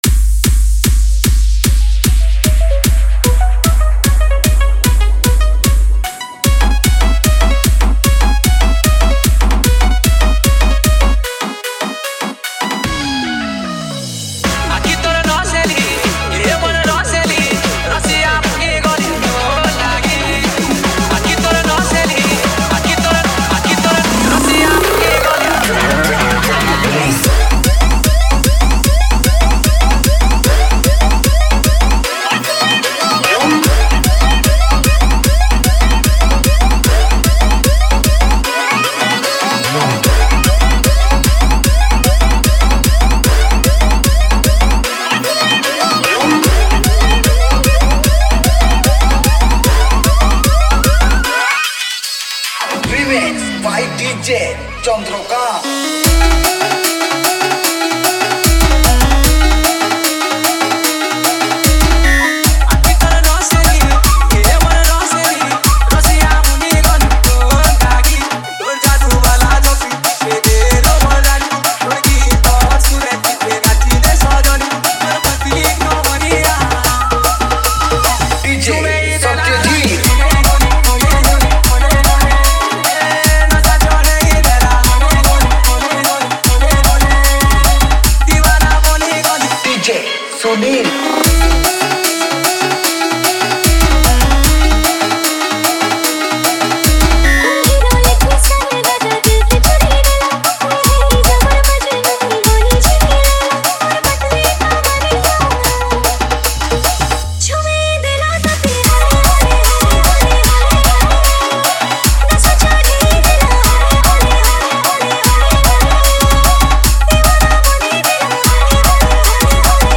Category:  New Sambalpuri Dj Song 2020